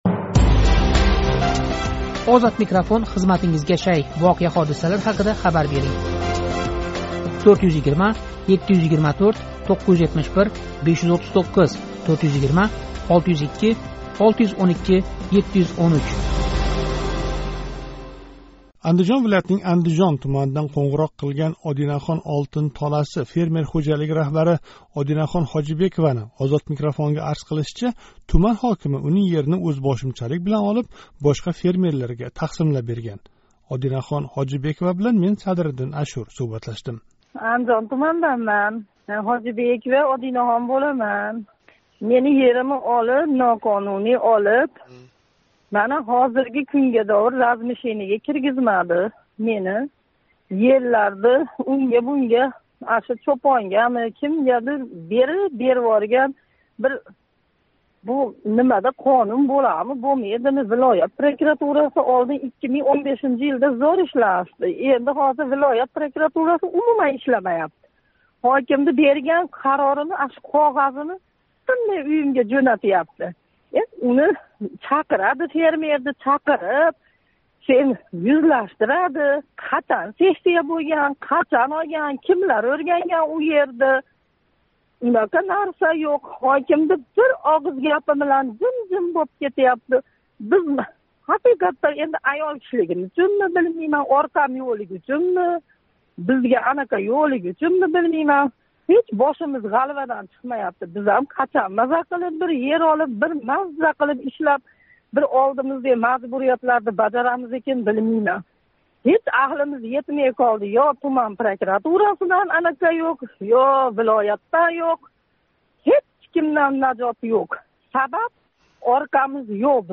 Муҳтарам муштарий, агар сизни ташвишга солаётган муаммолар бўлса, шунингдек, ўзингиз гувоҳи бўлаётган воқеа-ҳодисалар борасида Озодликка хабар бермоқчи бўлсангиз¸ бизга қўнғироқ қилинг.